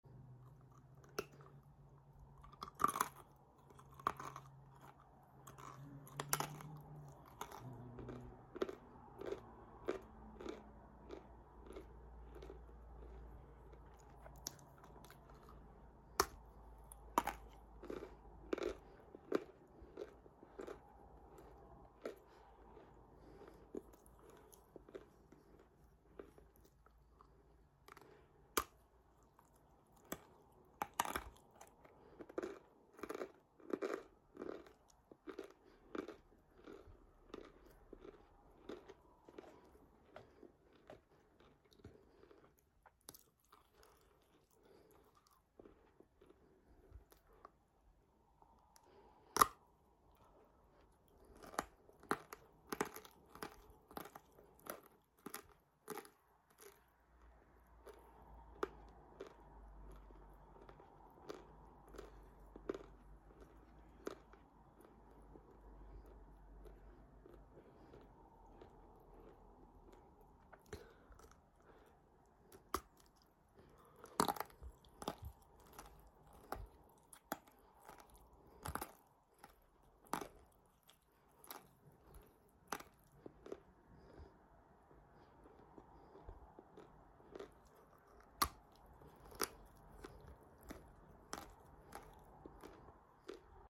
Dieser Ton ist für alle, die gerne knirschen.